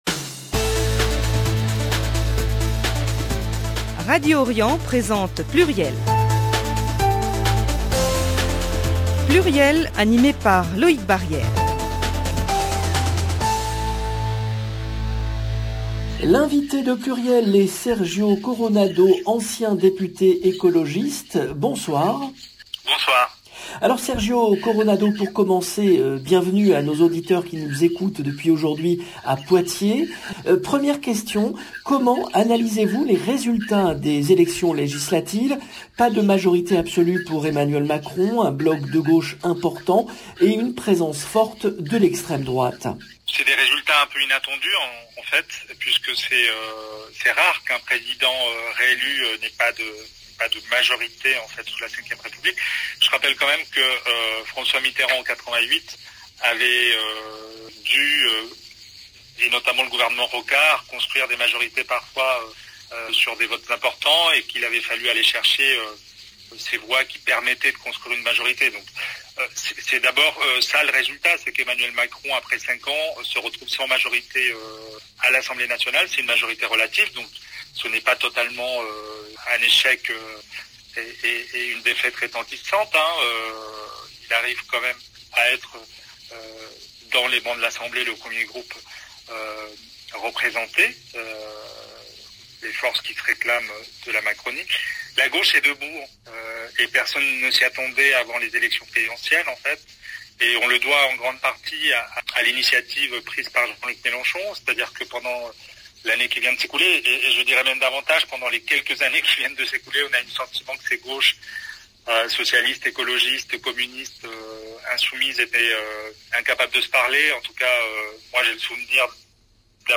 Sergio Coronado, ancien député écologiste